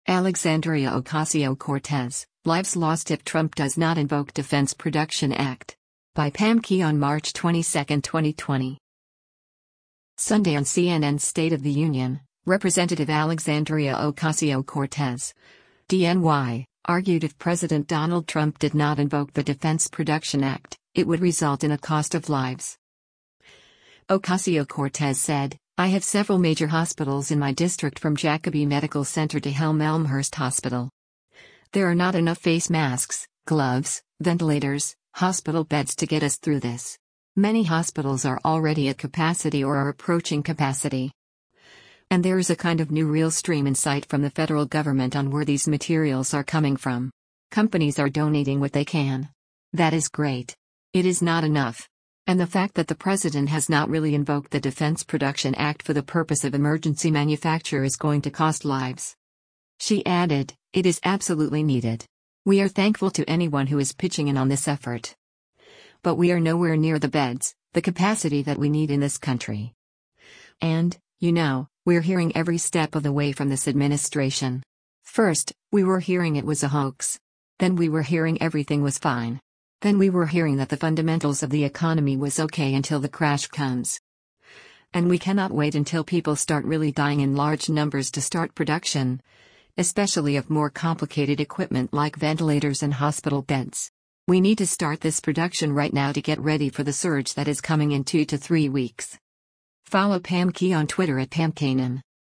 Sunday on CNN’s “State of the Union,” Rep. Alexandria Ocasio-Cortez (D-NY) argued if President Donald Trump did not invoke the Defense Production Act, it would result in a cost of lives.